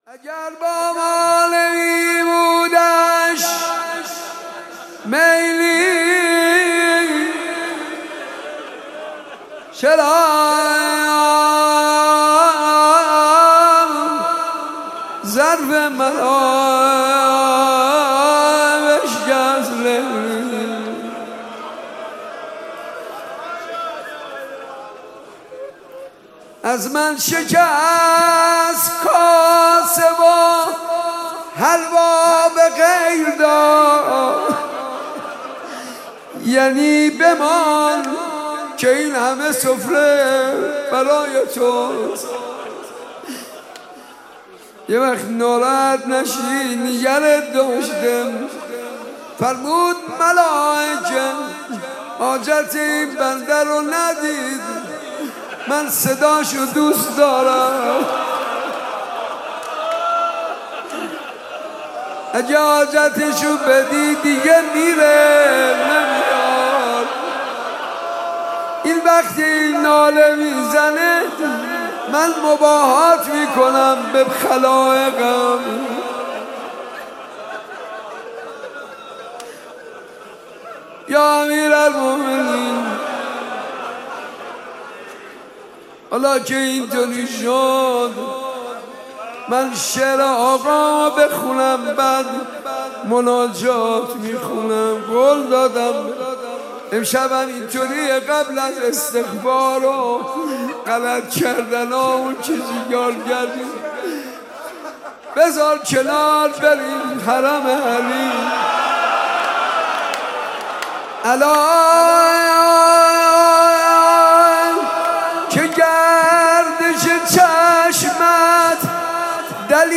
صوت شعر با نوای